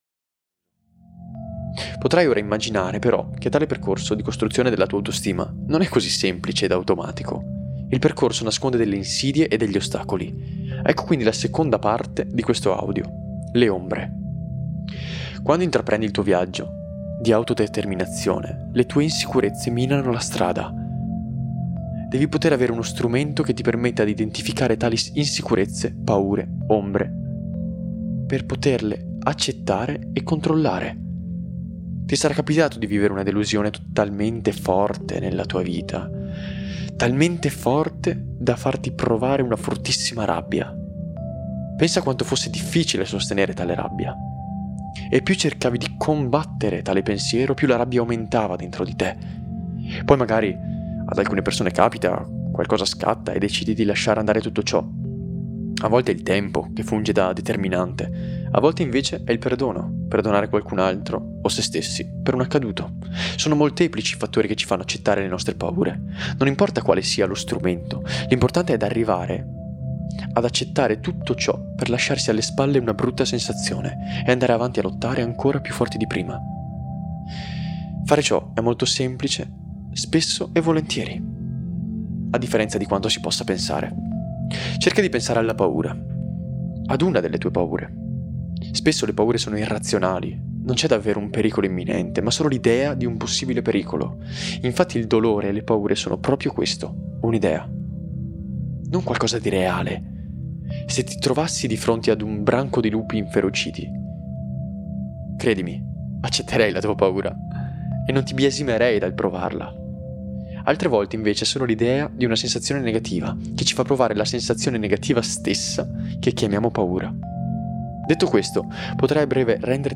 Grazie alla combinazione di tecniche neuroscientifiche, battiti binaurali e una fine struttura verbale, il tuo subconscio riuscirà finalmente a farti metabolizzare le informazioni di cui hai bisogno per passare da ragazzino frustrato, a un uomo formato che ha raggiunto la sua oasi sentimentale.